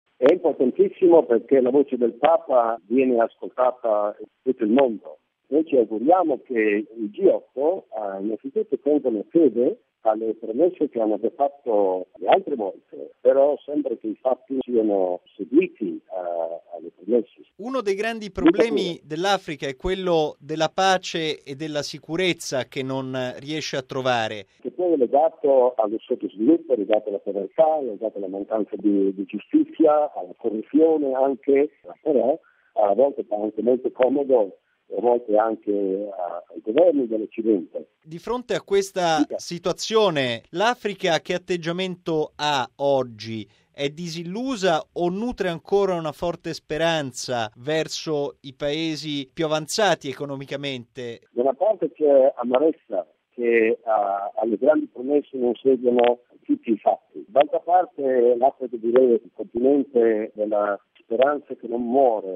E sull’appello rivolto da Papa al vertice dei G8 affinché vengano mantenute le promesse per la cooperazione allo sviluppo, in particolare per l’Africa, abbiamo raccolto il commento di mons. Giorgio Biguzzi, vescovo di Makeni in Sierra Leone.